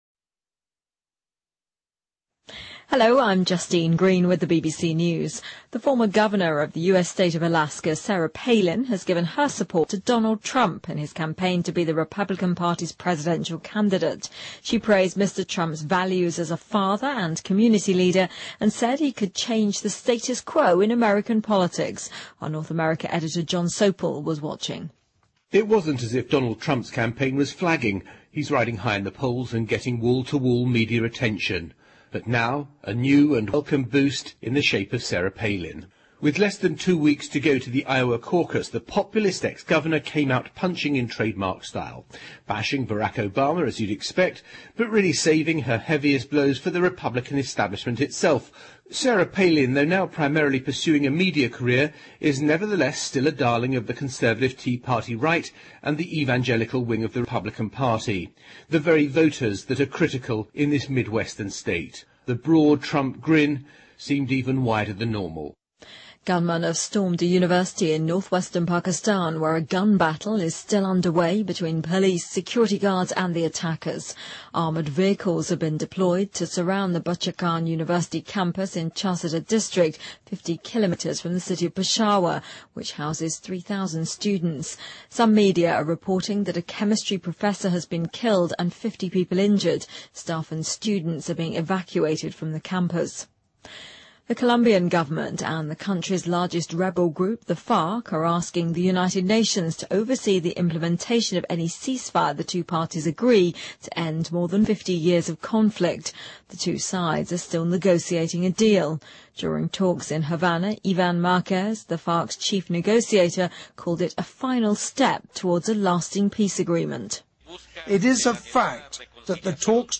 日期:2016-01-21来源:BBC新闻听力 编辑:给力英语BBC频道